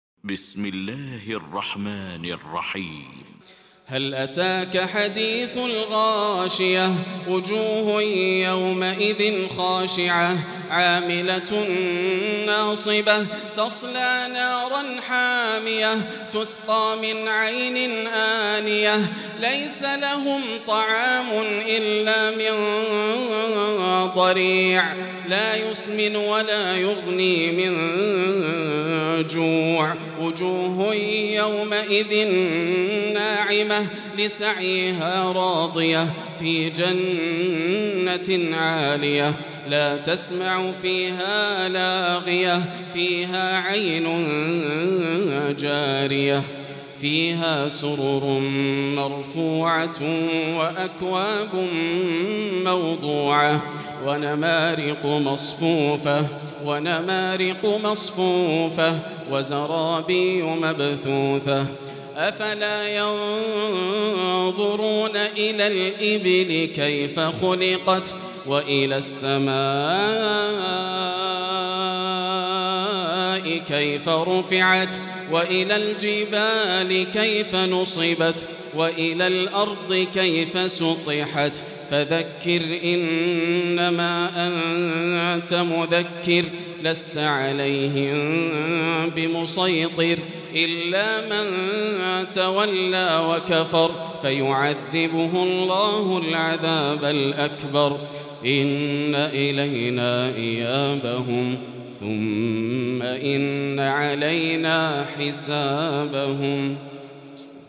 المكان: المسجد الحرام الشيخ: فضيلة الشيخ ياسر الدوسري فضيلة الشيخ ياسر الدوسري الغاشية